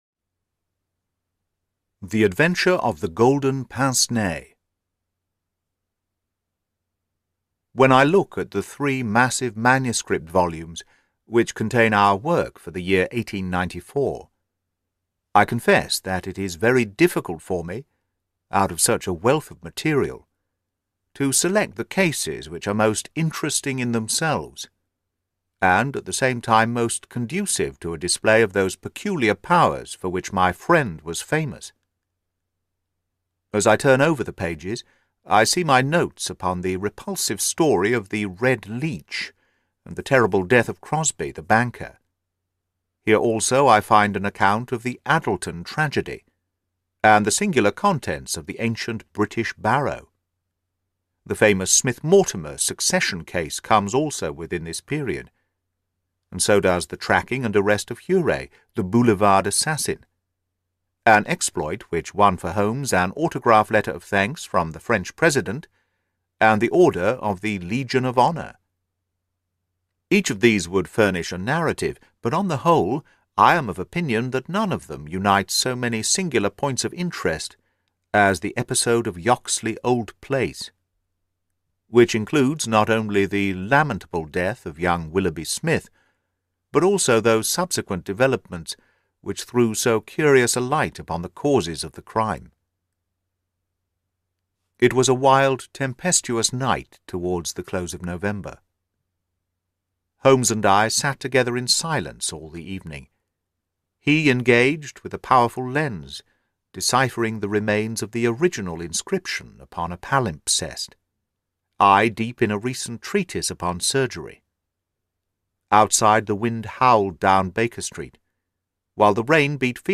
The Boscombe Valley Mystery: Deadly Clues Uncovered (Audiobook)